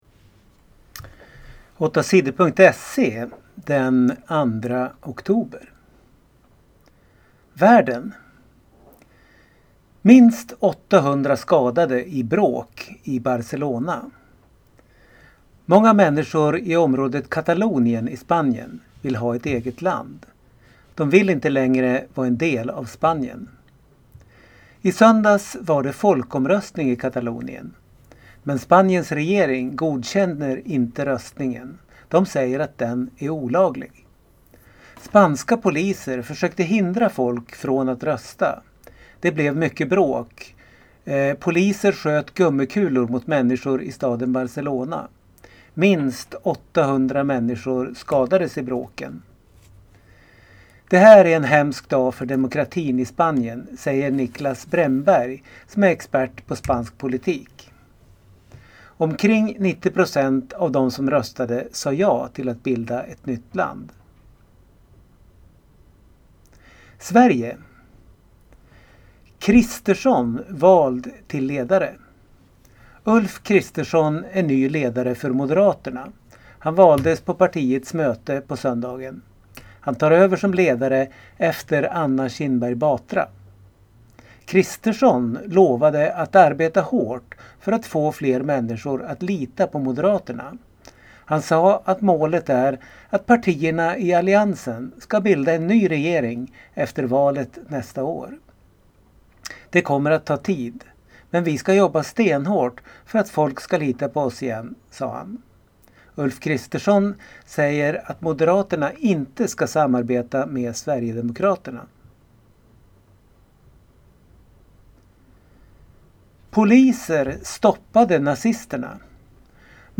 Lyssna på nyheter från måndagen den 2 oktober